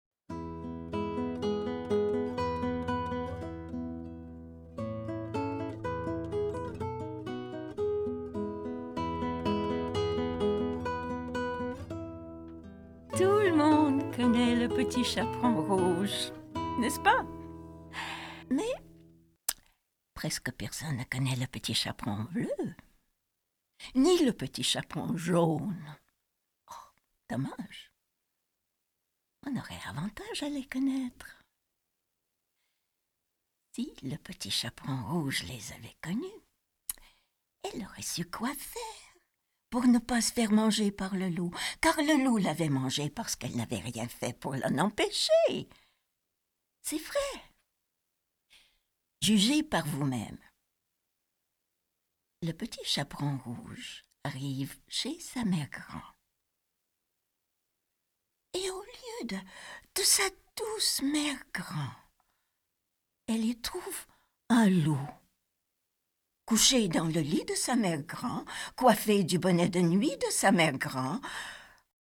Dans ce premier volume, Fanfreluche nous raconte l’histoire du Petit Chaperon Bleu et du Petit Chaperon Jaune. Accompagnée tout simplement d’une guitare acoustique, la voix enveloppante et chaleureuse de Fanfreluche promet de garder les tout petits enfants attentifs et fascinés jusqu’à la dernière page. Lu par Kim Yaroshevskaya Durée : 27 minutes × Guide des formats Les livres numériques peuvent être téléchargés depuis l'ebookstore Numilog ou directement depuis une tablette ou smartphone.